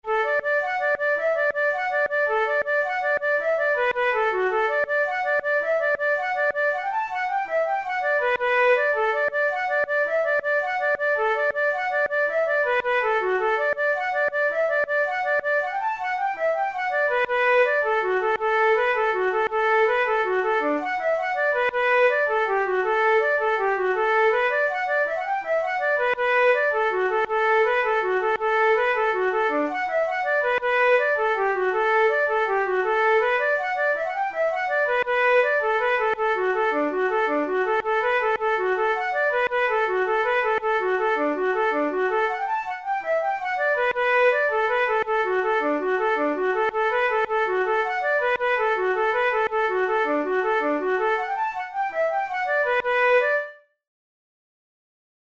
Traditional Irish jig
Categories: Jigs Traditional/Folk Difficulty: intermediate